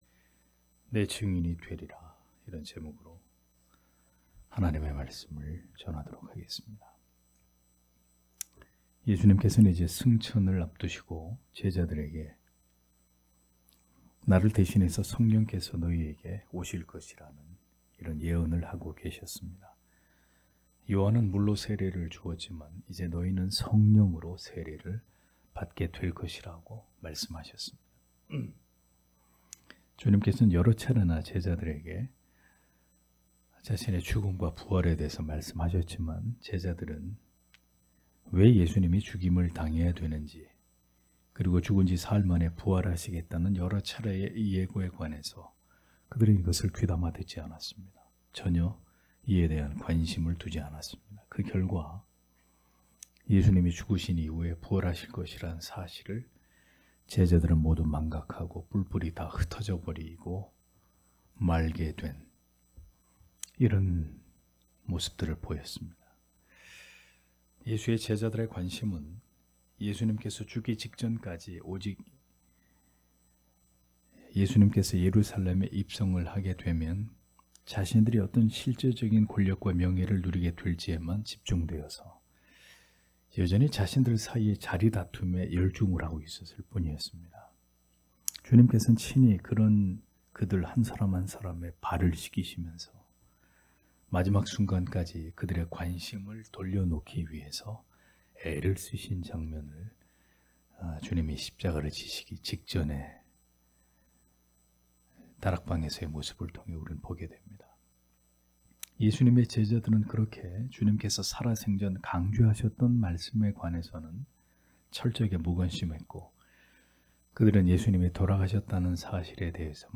금요기도회 - [사도행전 강해 03] 내 증인이 되리라 (행 1장 6- 8절)